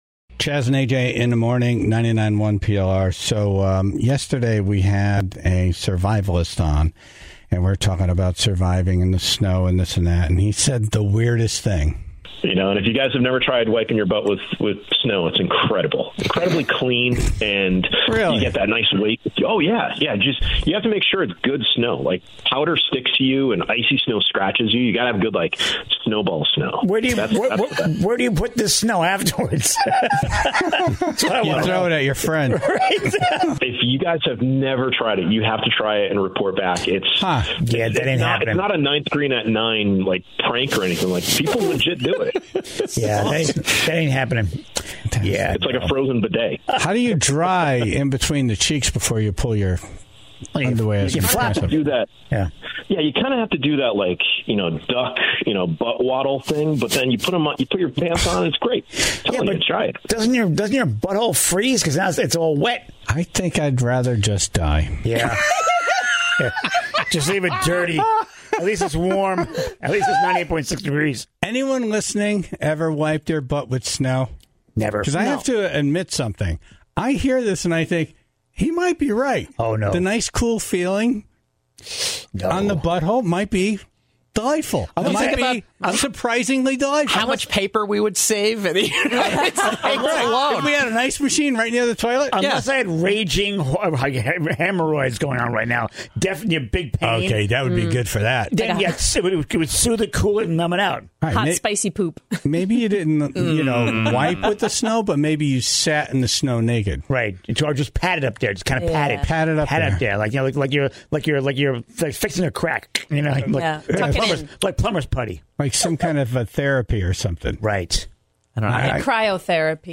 In honor of that moment, the Tribe called in with the things they are surprised to find delightful, from a fresh port-o-potty to picking scabs.